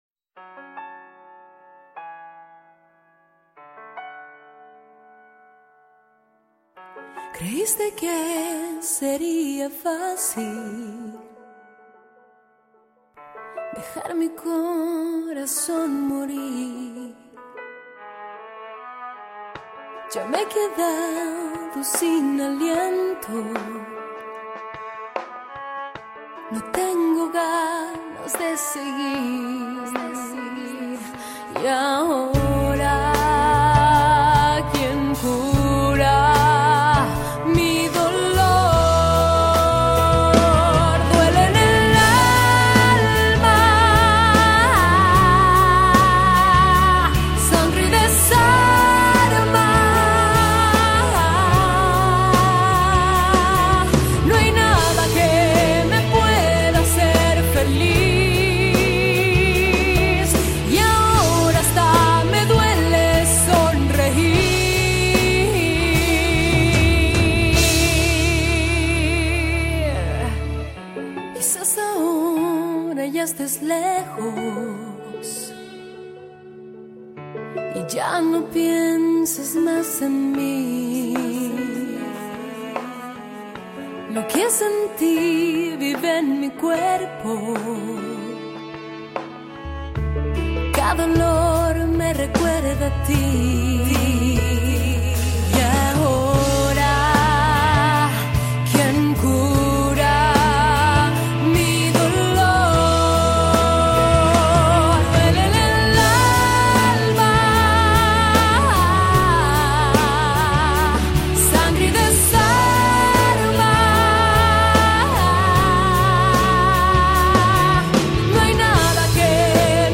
歌声甜美，曲风为流行